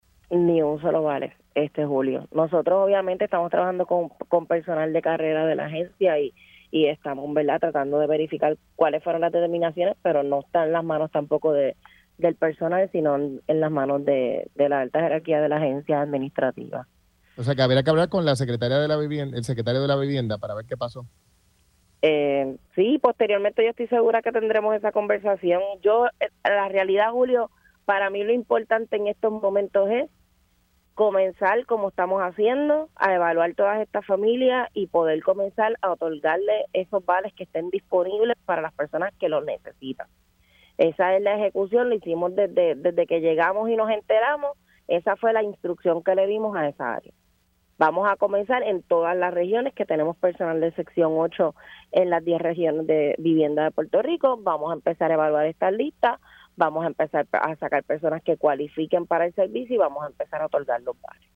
No obstante, tan pronto nosotros llegamos a la agencia, estuvimos reuniéndonos con cada una de las áreas, viendo de qué había disponibilidad, observando los servicios importantes de la gente y descubrimos con el área de sección 8 que en 2024 no se otorgó un solo vale, habiendo disponible casi 700 vales para 700 familias, para ser específica, 686 para familias en Puerto Rico“, indicó en entrevista para Pega’os en la Mañana.